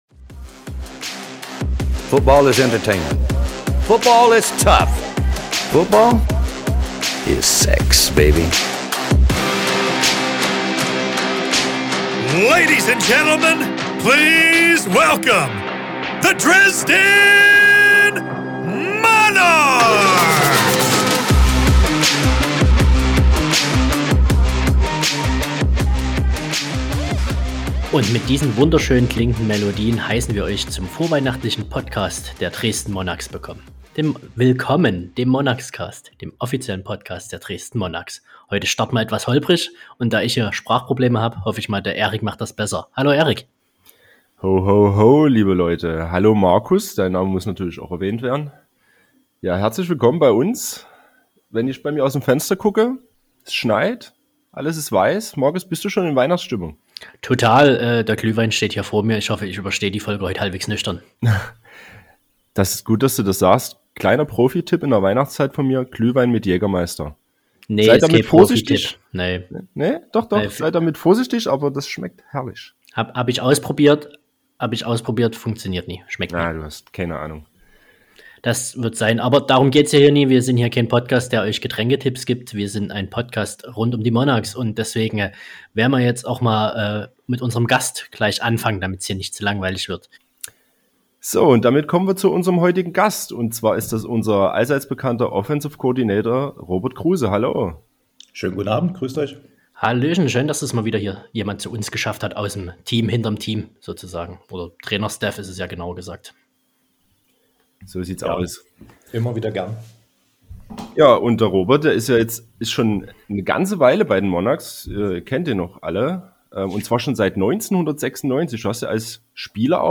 Außerdem wird immer ein interessanter Gast in einem Interview Rede und Antwort stehen.